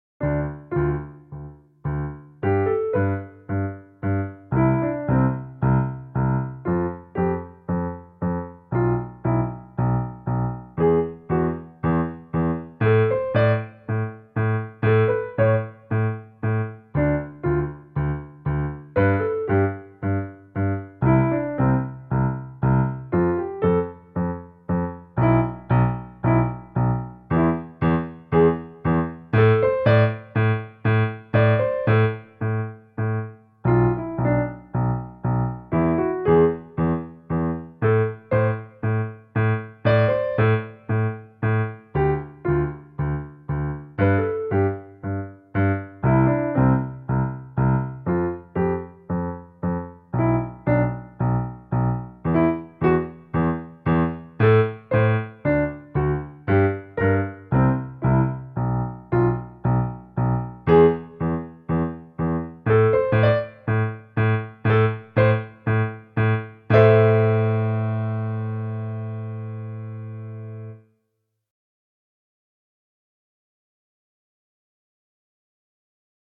Drei Soli mit Miniskalen
Hier nun sind Aufnahmen mit dem Klavier.